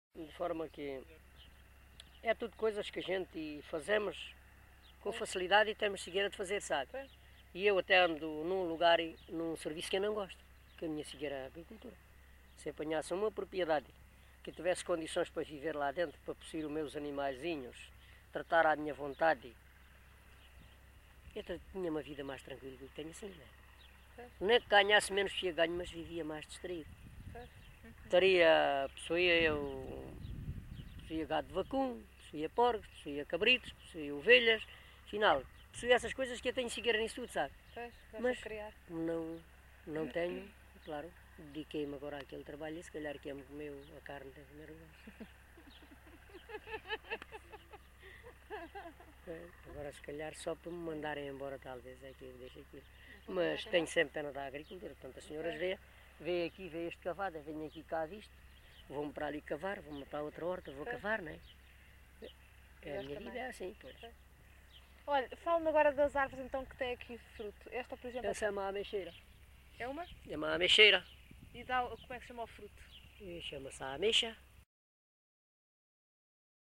LocalidadeMelides (Grândola, Setúbal)